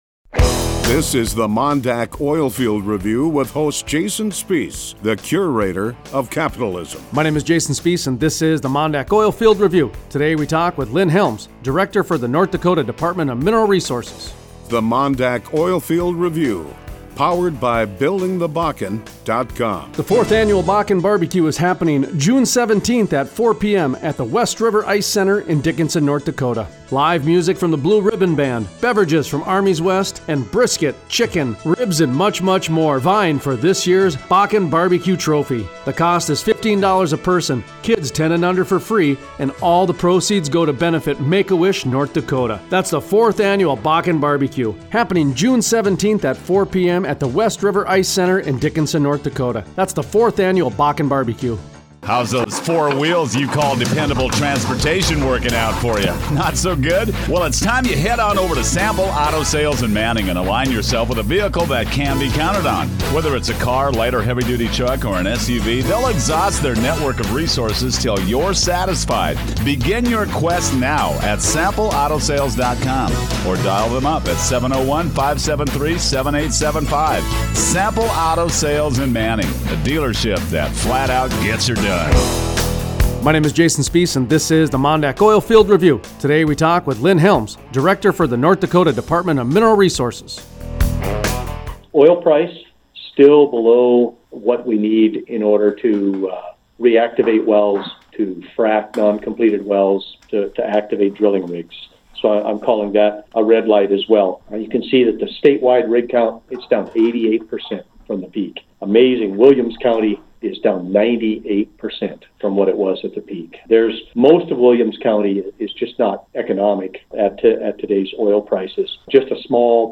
Interview:  Lynn Helms, director, North Dakota Dept of Minerals